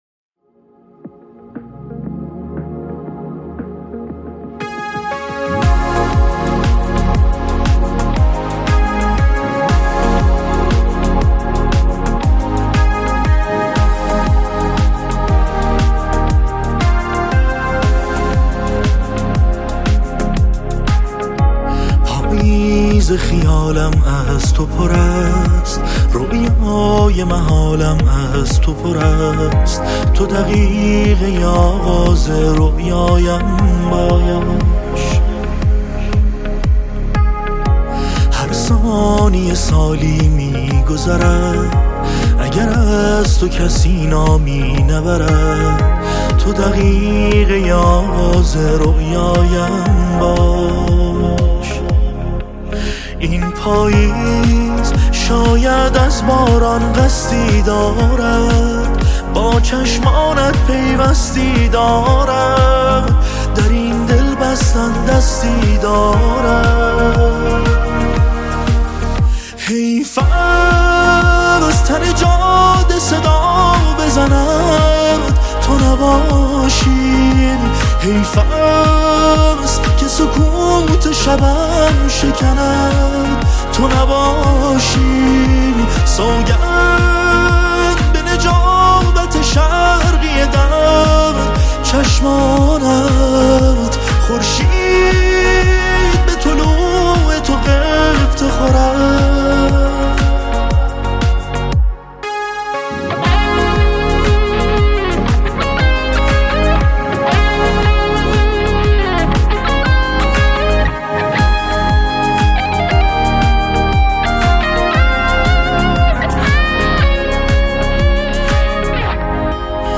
صدای اسمانی